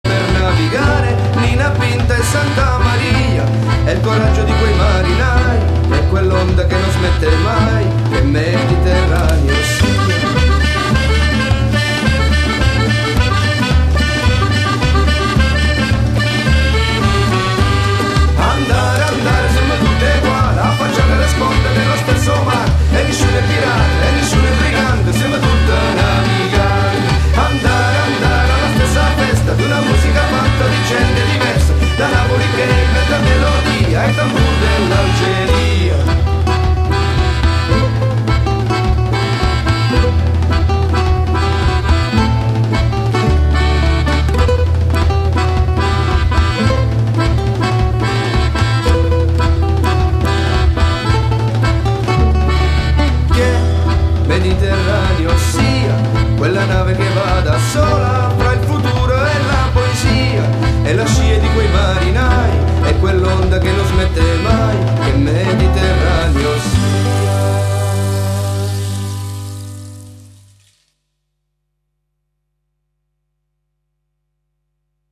Voce e Chitarra
Fisarmonica
Percussioni